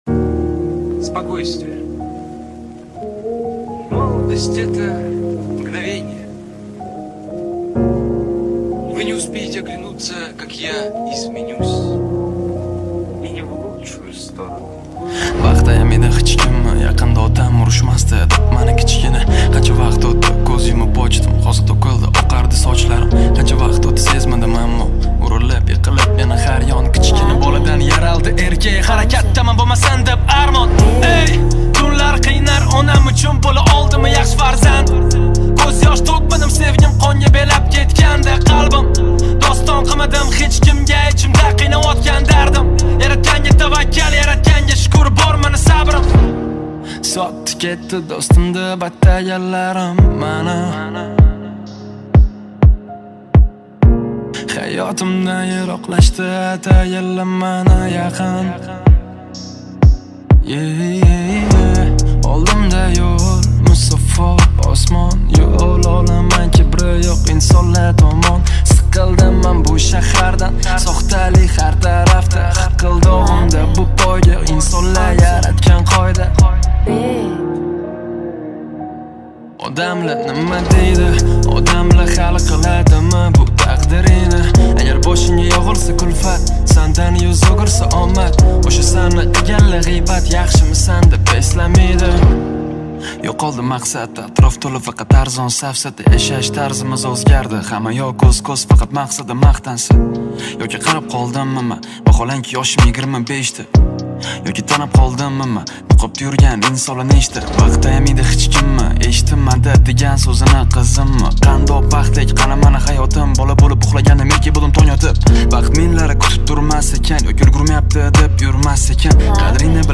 Узбекская песня